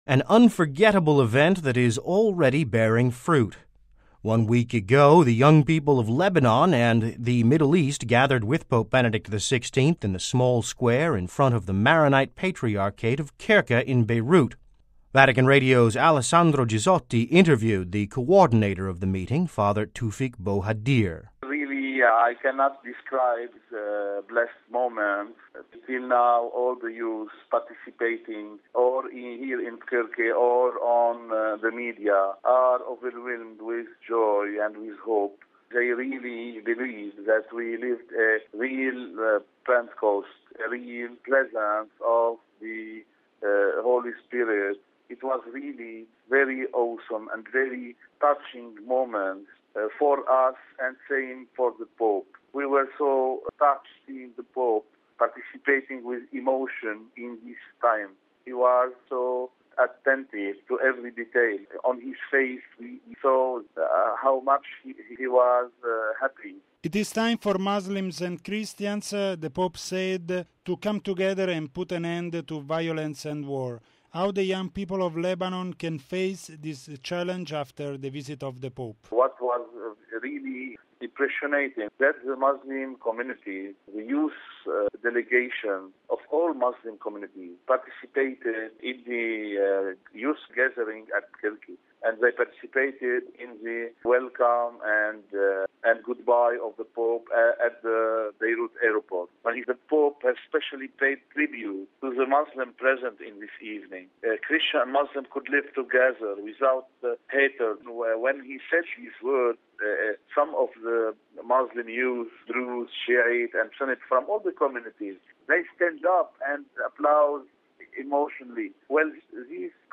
(Vatican Radio) An unforgettable event that is already bearing fruit: a week ago, the young people of Lebanon and the Middle East gathered with Pope Benedict XVI in the small square in front of the Maronite Patriarchate of Bkerke in Beirut.